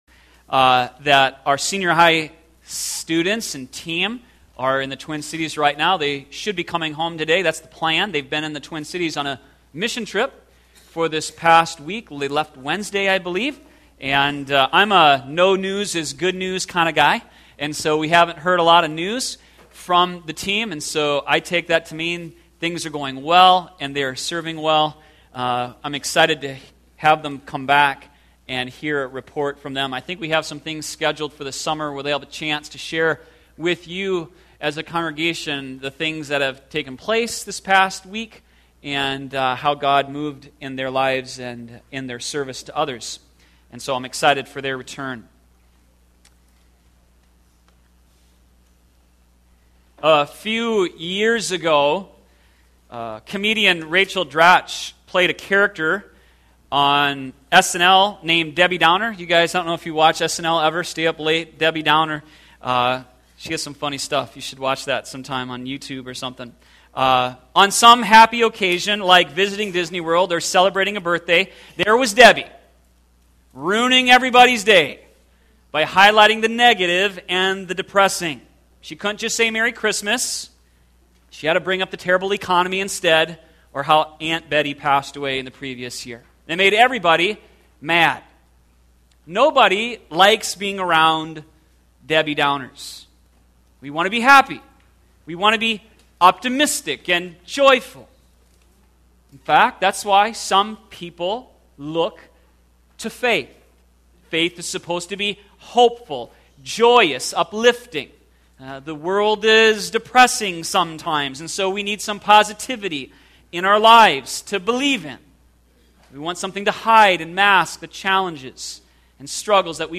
sermon62214.mp3